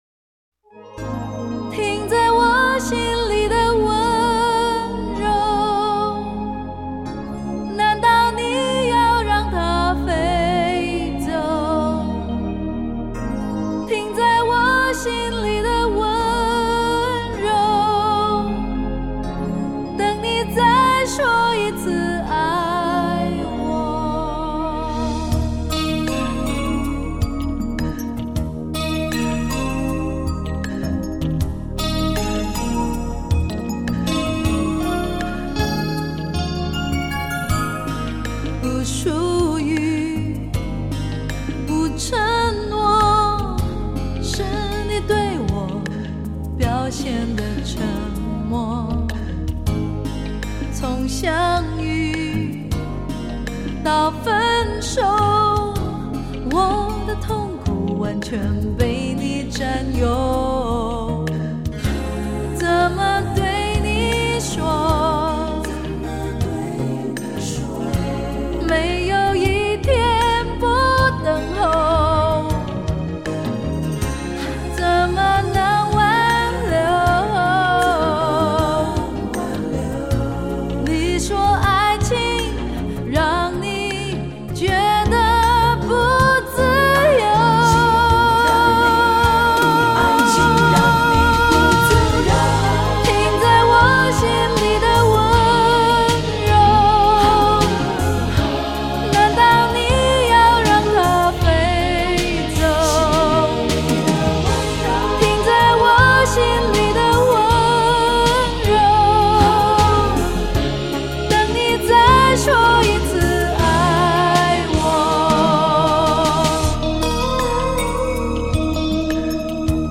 经典怀旧 好听动人
这张唱片是属于都市性质的新情歌专辑。
呈现出简易上口的流行音乐的基调，舒缓、轻柔